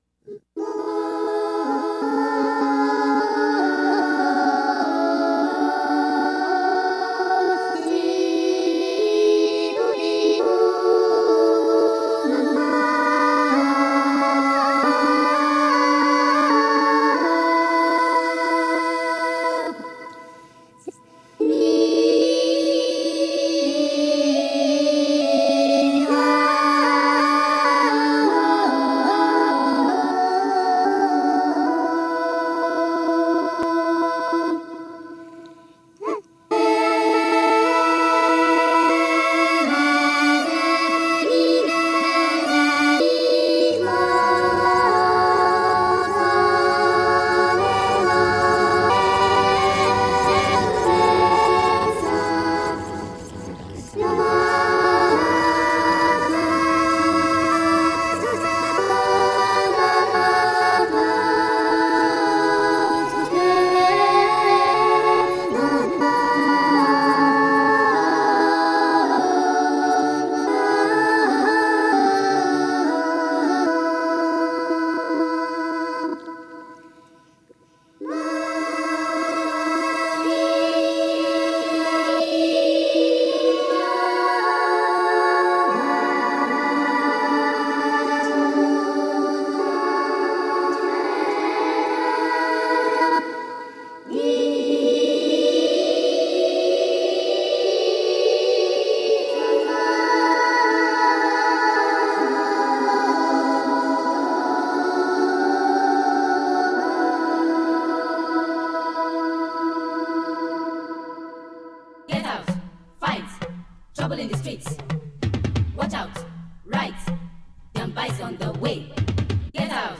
Beats Indie
highly textured, beautiful tones